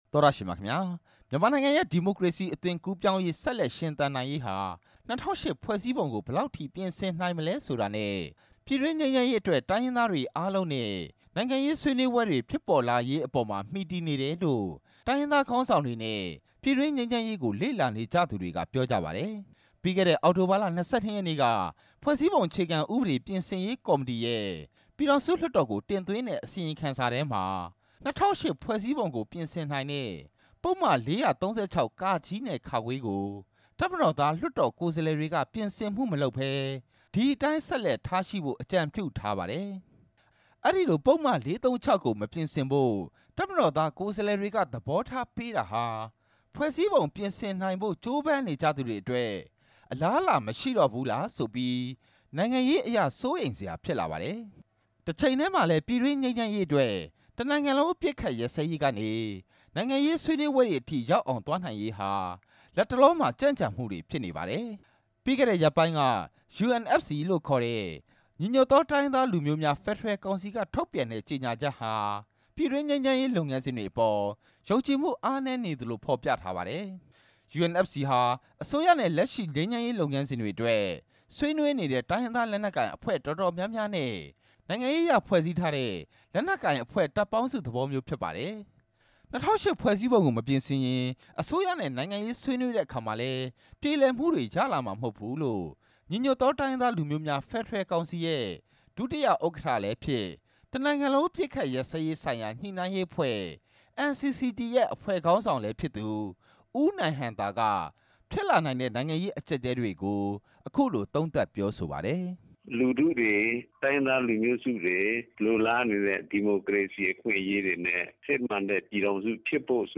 တိုင်းရင်းသားနဲ့ အရပ်ဘက်အဖွဲ့အစည်း ခေါင်းဆောင်တွေ အမြင်